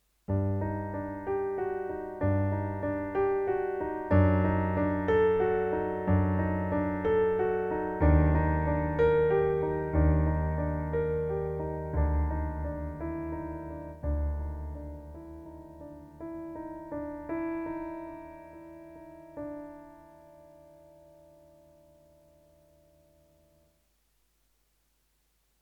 Lecture musicale